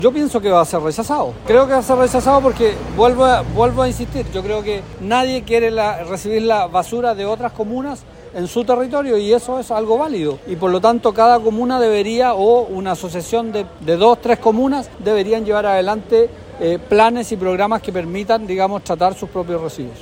Sobre esto, el alcalde de Teodoro Schmidt, Baldomero Santos, dijo que ese tipo de proyectos no tienen futuro porque “nadie quiere la basura de otros”.